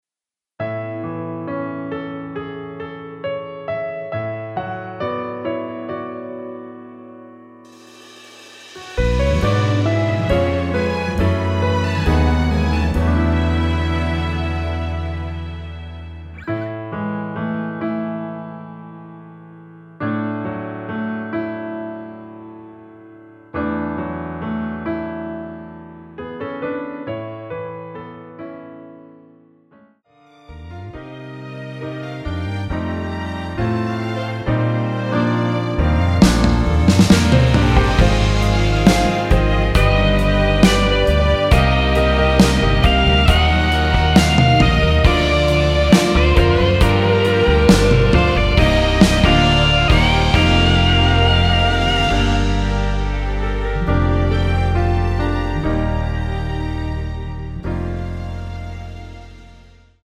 (-2) 내린 MR
앨범 | O.S.T
◈ 곡명 옆 (-1)은 반음 내림, (+1)은 반음 올림 입니다.
앞부분30초, 뒷부분30초씩 편집해서 올려 드리고 있습니다.
중간에 음이 끈어지고 다시 나오는 이유는